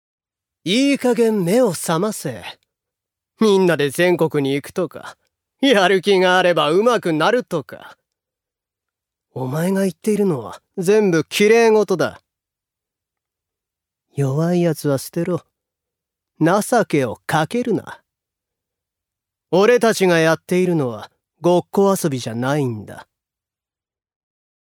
所属：男性タレント
セリフ１